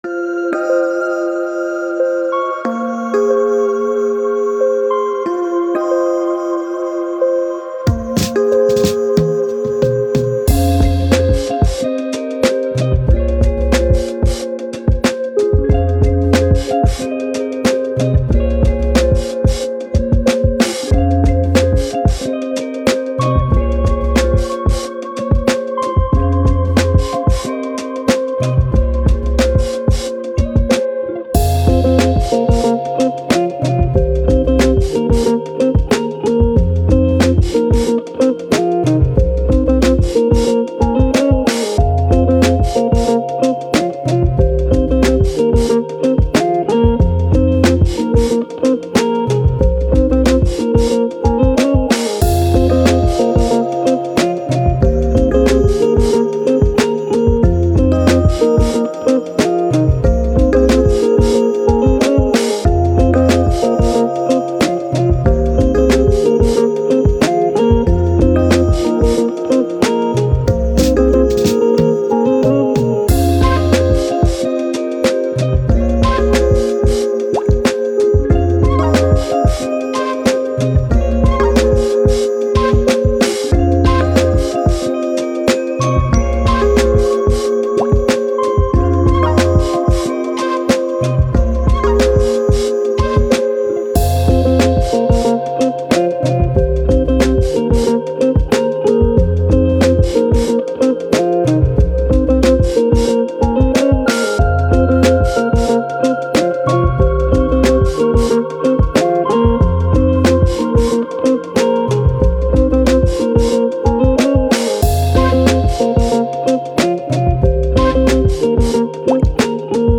Hip Hop, Vintage, Vibe, Chilled